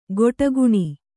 ♪ goṭaguṇi